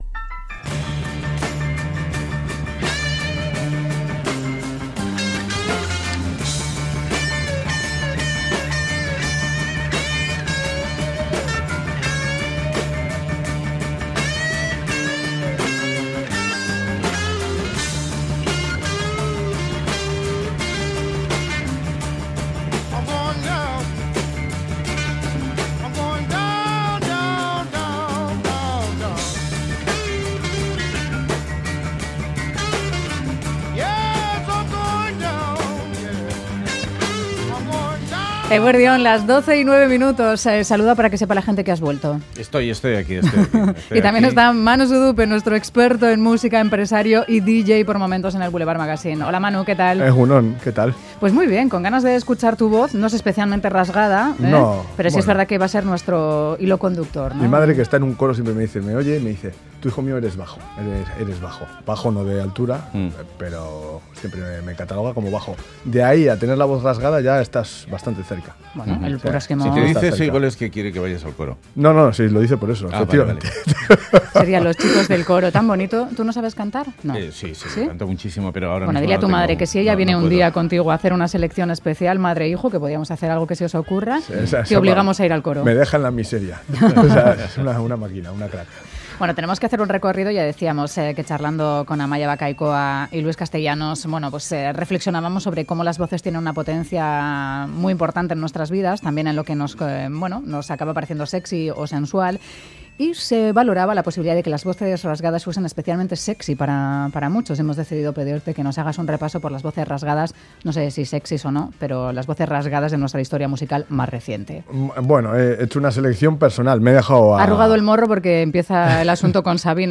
Voces rasgadas de la música